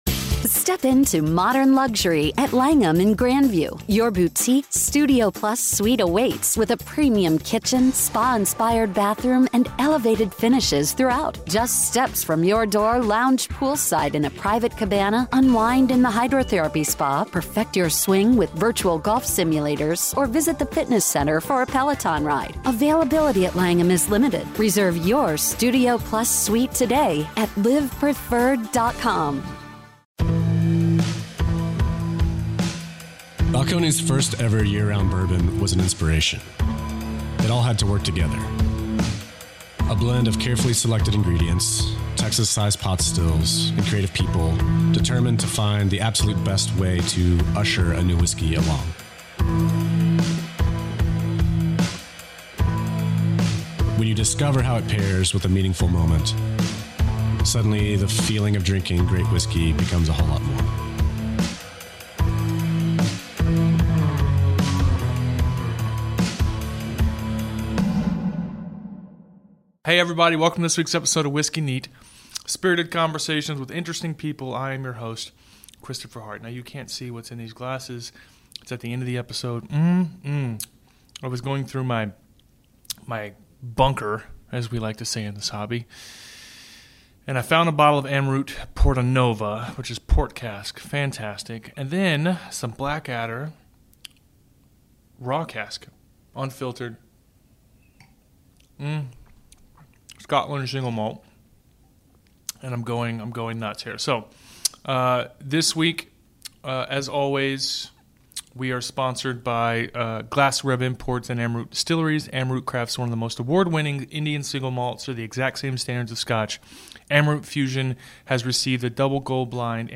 Whiskey Neat is a Radio show on iTunes and ESPN 97.5 FM in Houston and is brought to you every week by the following sponsors.